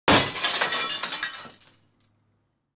Play Glass Shattering - SoundBoardGuy
Play, download and share glass shattering original sound button!!!!
glass-shattering.mp3